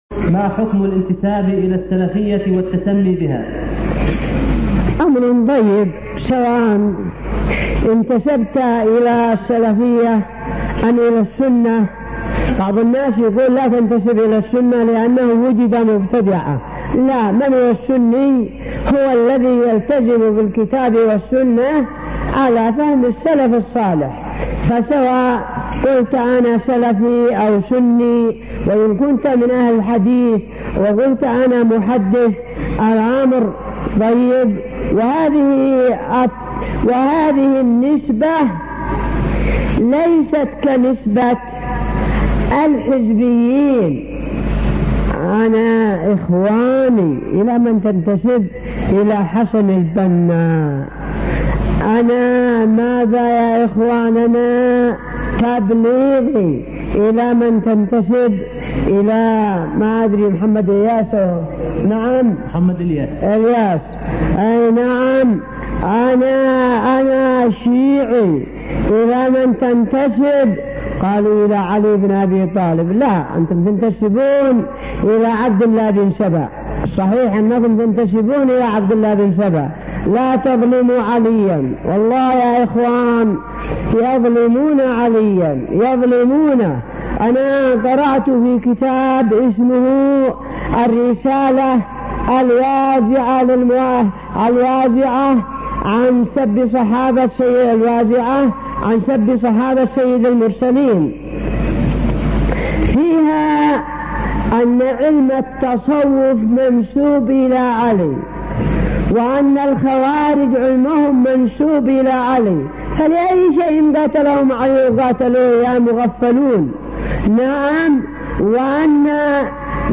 Fatwa
Diambil dari kaset “al-Qaul an-Naqy Fii Ma’na Salafy”.